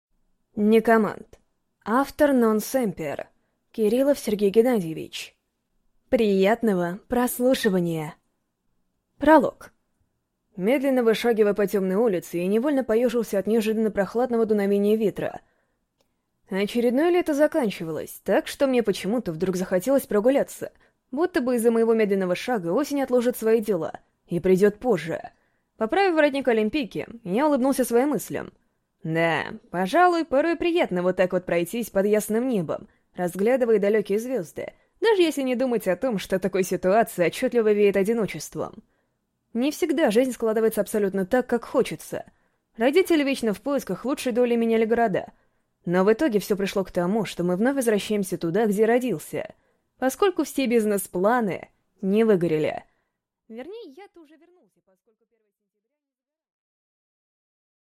Аудиокнига Некомант | Библиотека аудиокниг